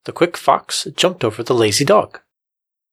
I am running librosa.pyin on a speech audio clip, and it doesn't seem to be extracting all the fundamentals (f0) from the first part of the recording.
1. There are some words/parts of a word that are difficult to hear: they have low energy and when listening to them alone it doesn't sound like a word, but only when coupled with nearby segments ("the" is very short and sounds more like "z").
2. Some words are divided into parts (e.g. "fo"-"x").